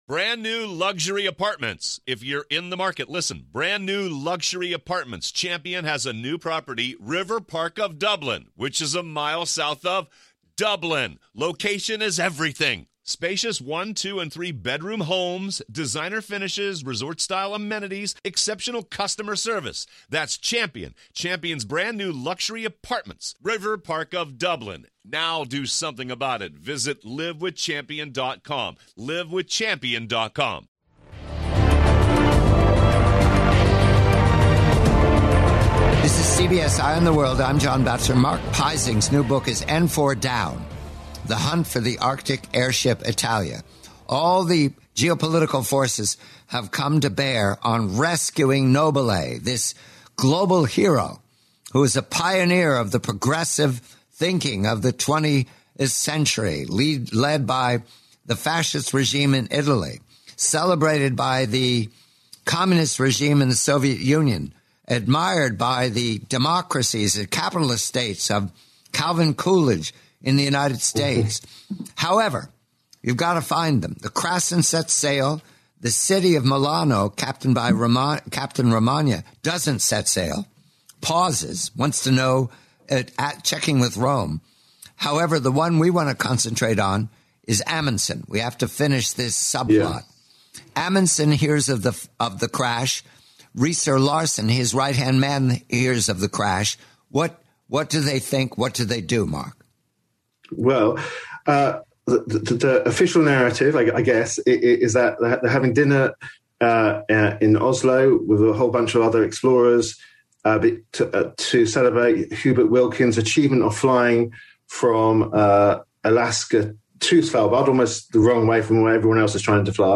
the complete, forty-minute interview, Arctic exploration